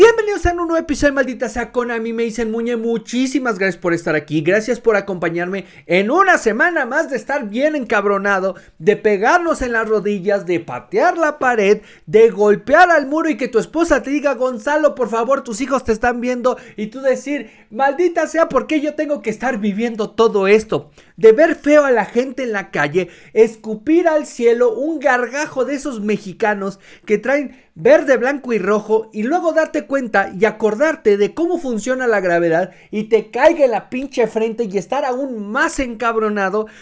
dub_male_style.wav